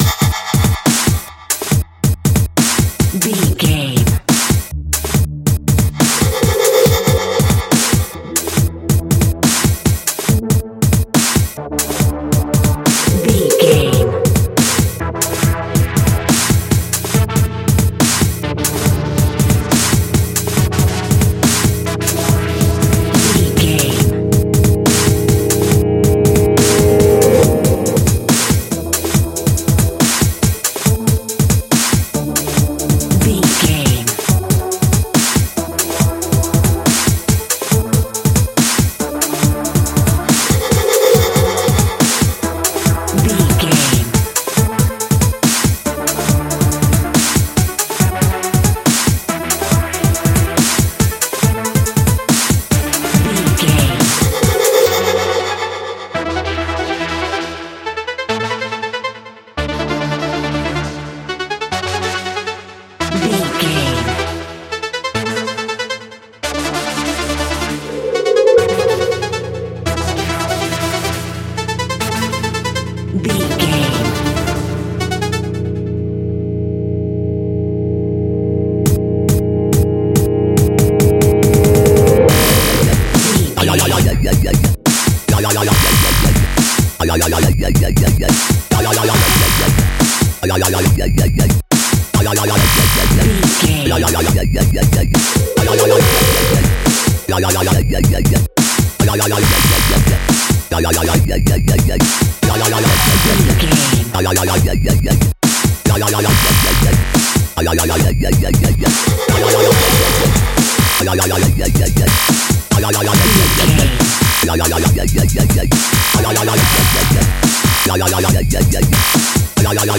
Dubstep Music Track.
Epic / Action
Fast paced
Aeolian/Minor
F#
aggressive
dark
energetic
drum machine
synthesiser
breakbeat
synth leads
synth bass